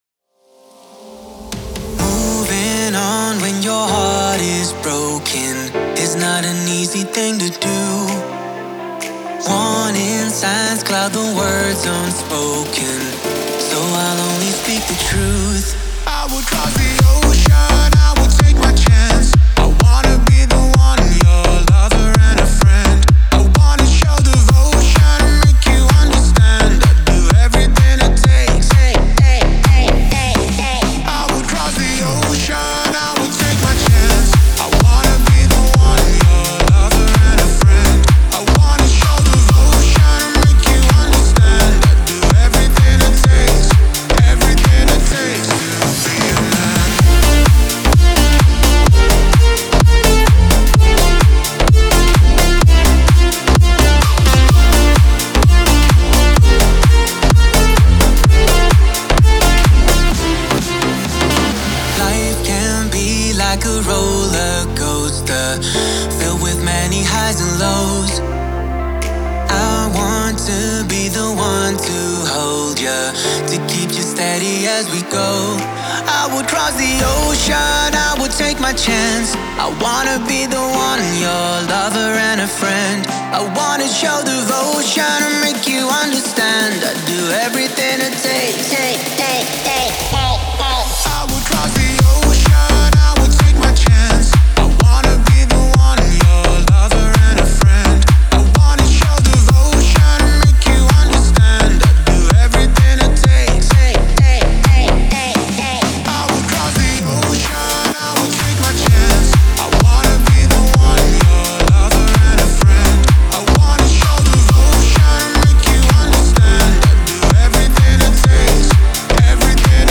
зажигательная поп-песня